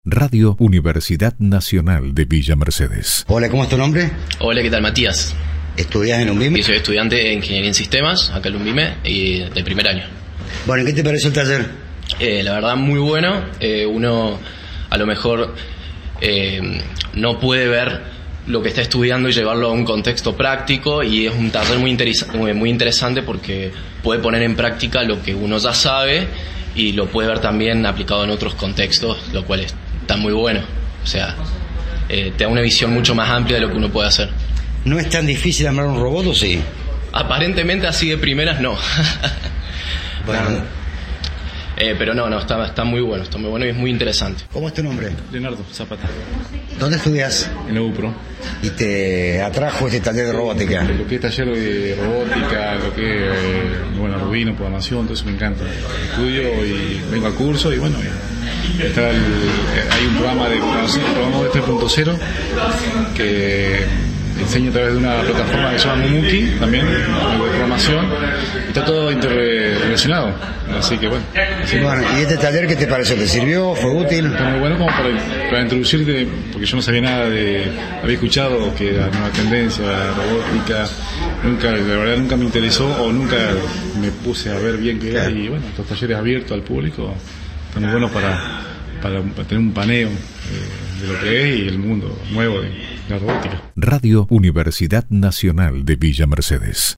ALUMNOS-EN-TALLER-DE-ROBOTICA.mp3